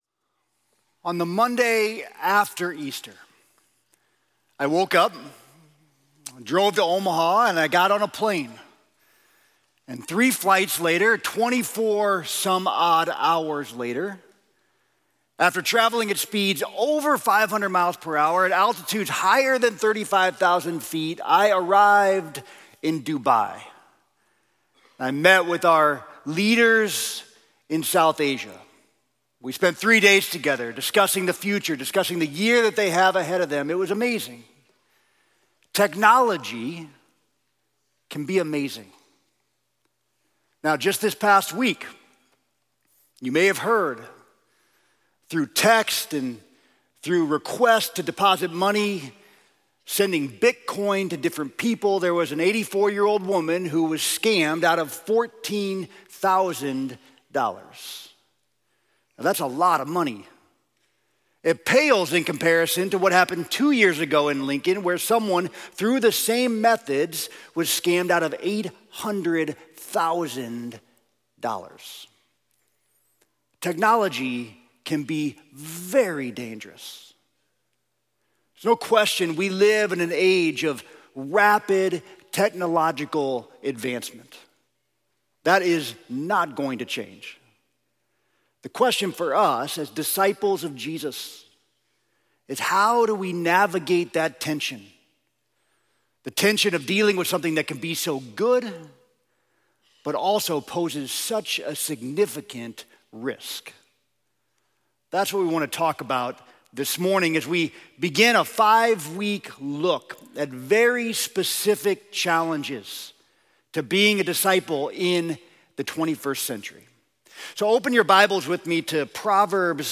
Sermon: Technology